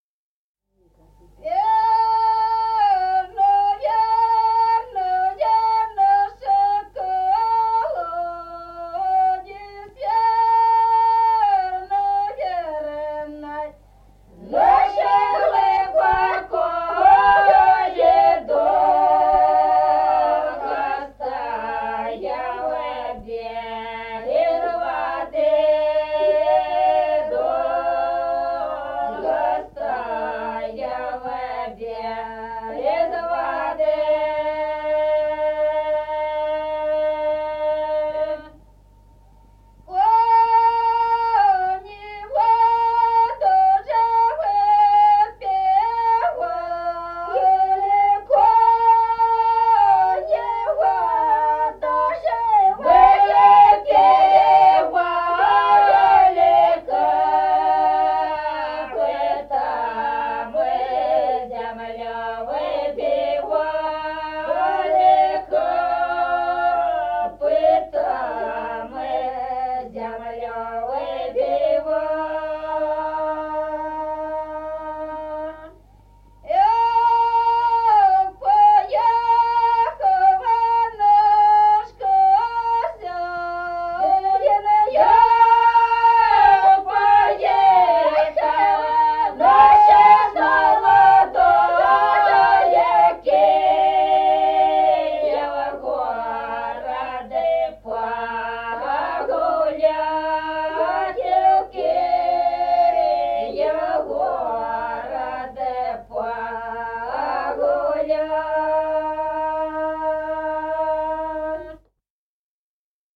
Песни села Остроглядово. Верный наш колодезь.